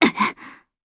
Valance-Vox_Damage_jp_01.wav